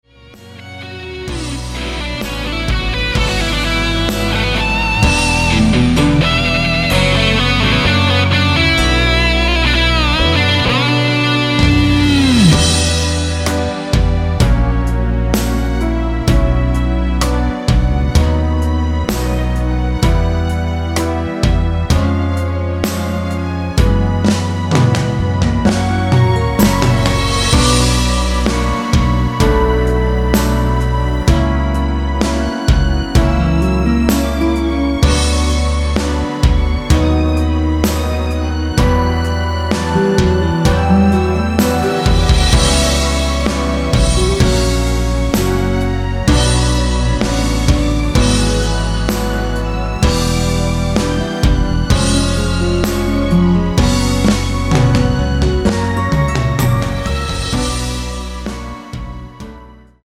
원키에서 (-2) 내린 MR 입니다.(미리듣기 참조)
Bm
앞부분30초, 뒷부분30초씩 편집해서 올려 드리고 있습니다.
중간에 음이 끈어지고 다시 나오는 이유는